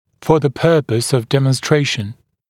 [fə ðə ‘pɜːpəs əv ˌdemən’streɪʃn][фо зэ ‘пё:пэс ов ˌдэмэн’стрэйшн]для наглядности; с целью демонстрации